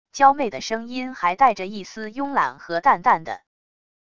娇媚的声音还带着一丝慵懒和淡淡的wav音频